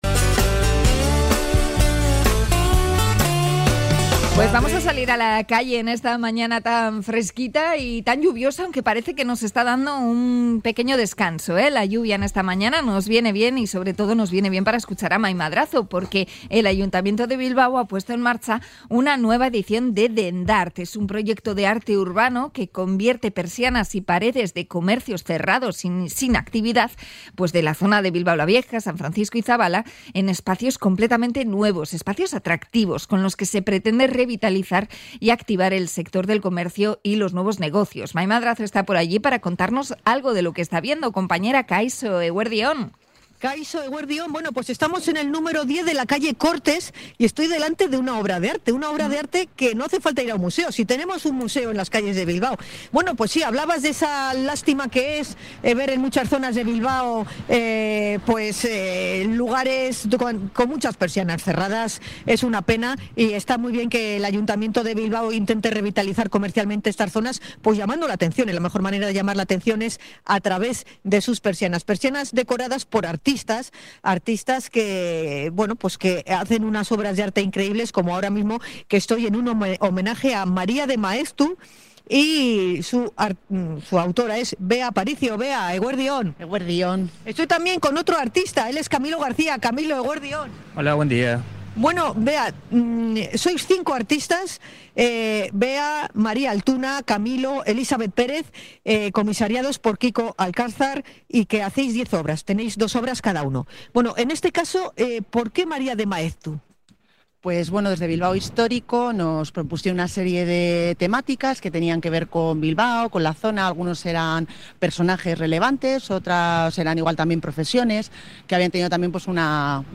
Hemos arrancado nuestro recorrido en el número 10 de la calle Cortes, donde constatamos que el arte ya está en plena calle.